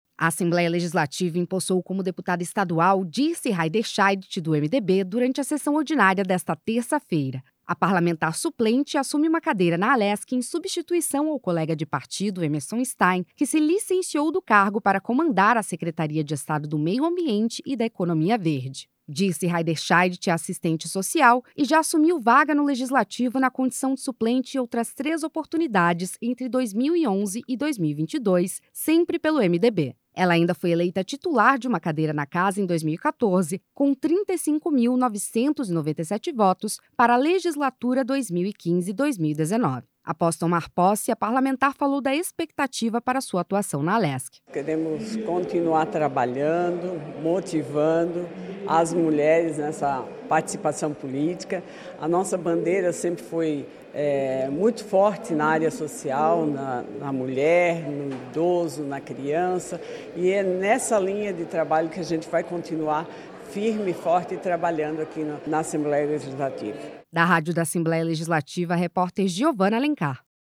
Entrevista com:
- Dirce Heiderscheidt (MDB), deputada estadual.
Repórter